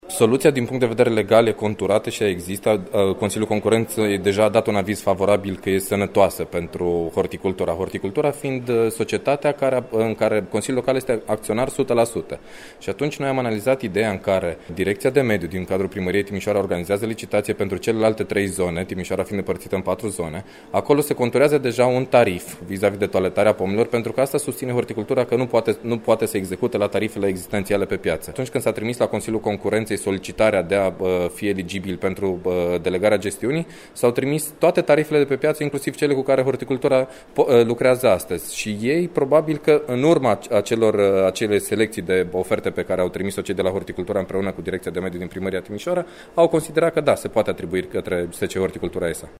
Liderul de grup al consilierilor liberali, Adrian Lulciuc: